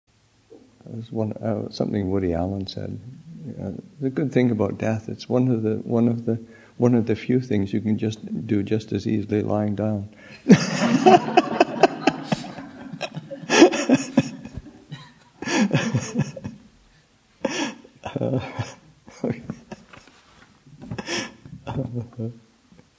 2014 Thanksgiving Monastic Retreat, Session 2 – Nov. 23, 2014